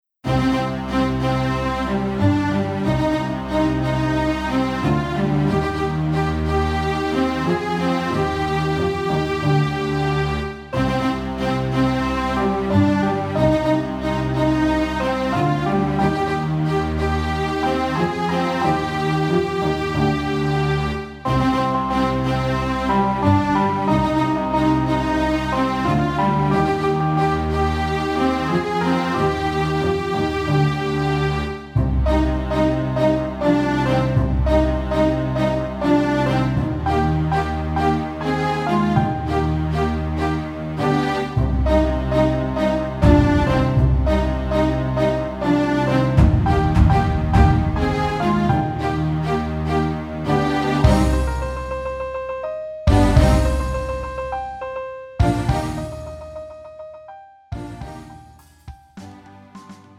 음정 -1키 2:24
장르 가요 구분 Pro MR